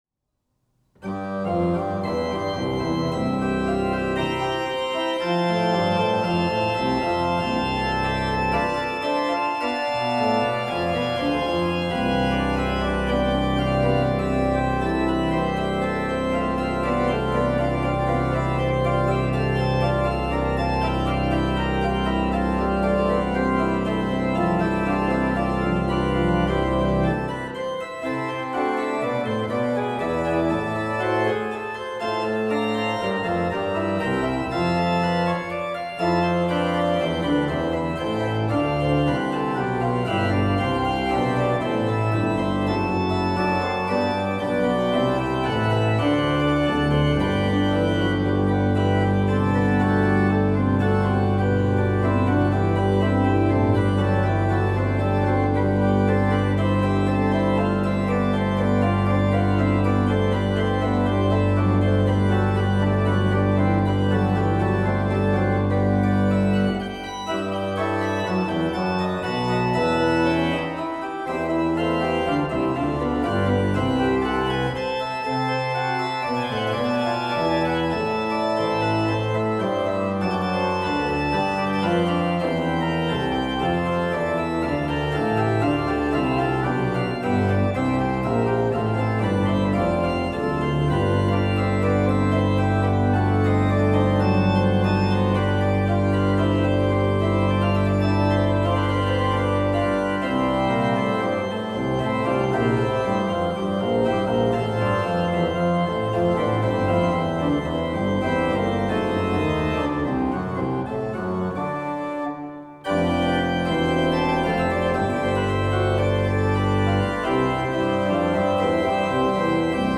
More organ pieces